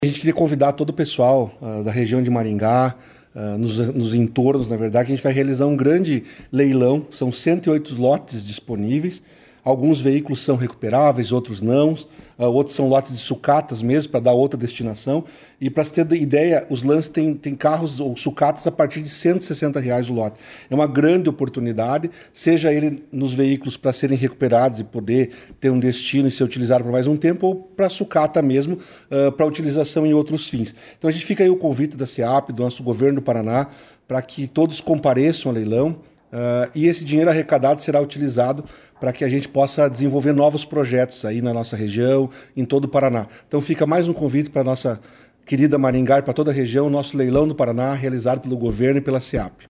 Sonora do secretário Estadual de Administração e Previdência, Elisandro Pires Frigo, sobre o leilão de veículos e sucatas que vai acontecer em Maringá